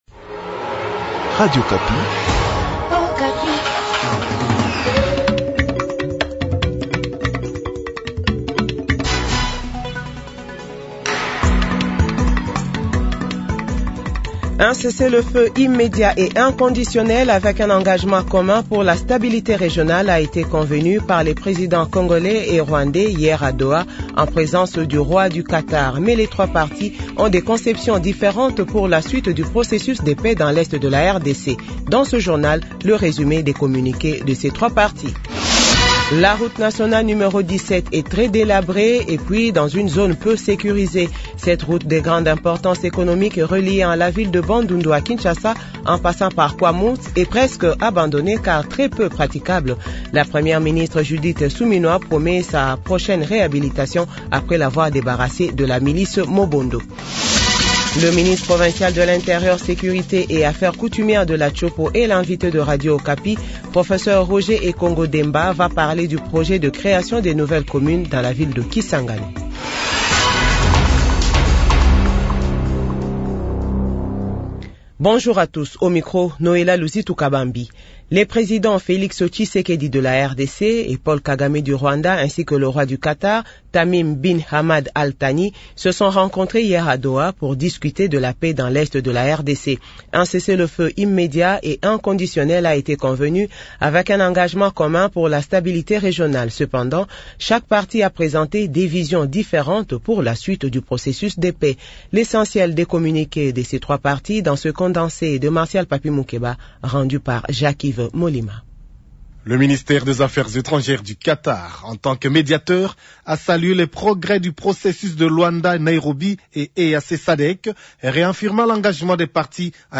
Journal 12h